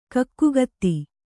♪ kakkugatti